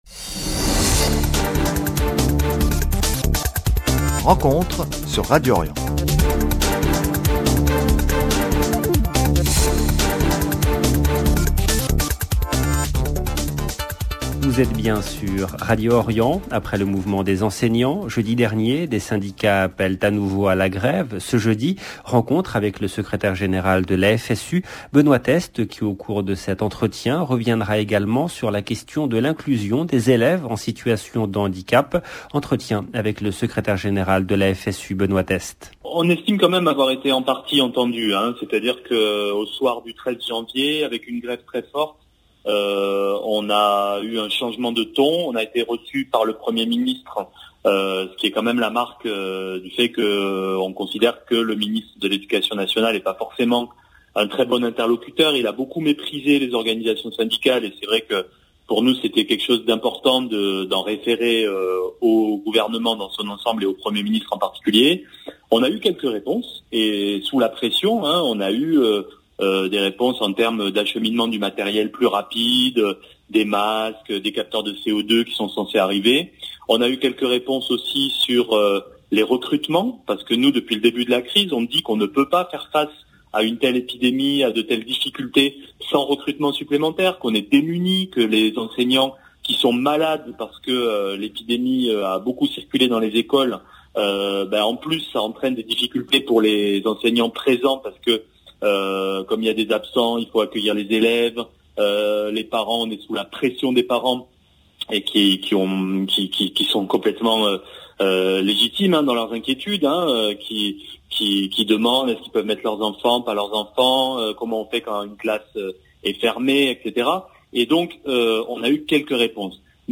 Entretien : 0:00 17 min 52 sec